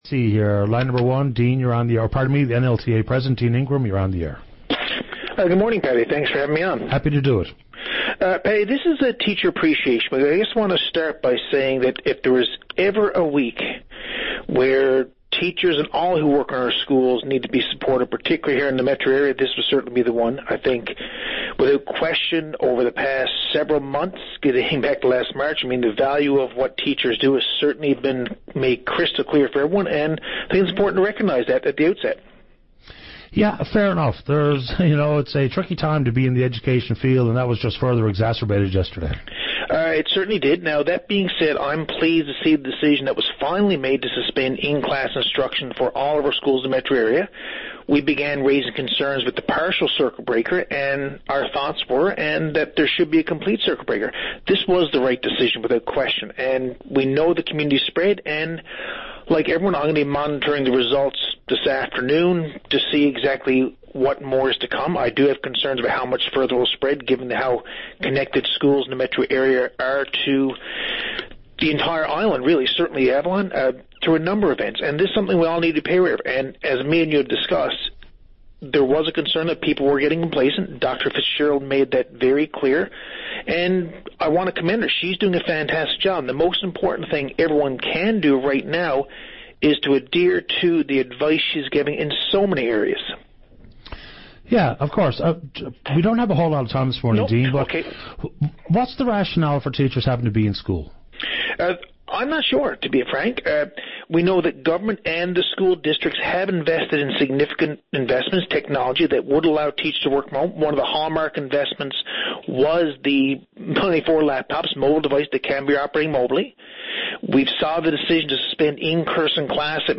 Media Interview - VOCM Open Line Feb11, 2021